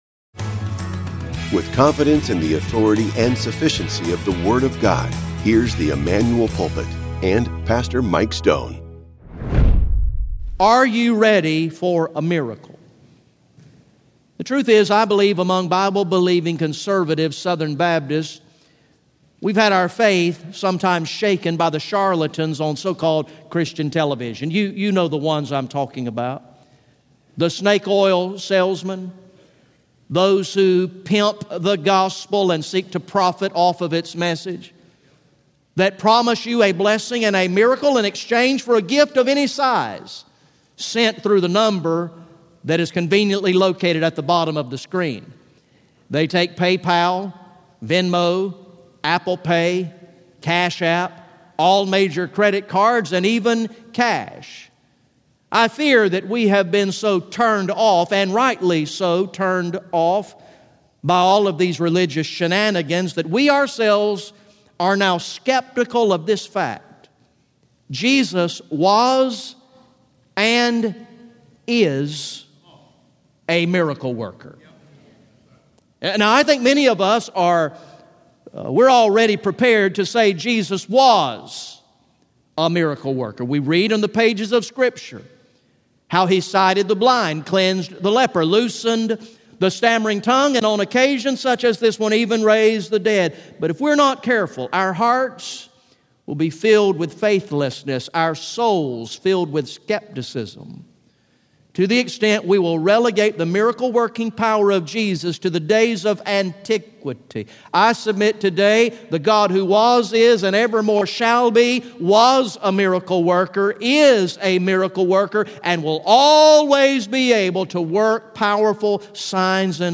sermon
Sunday AM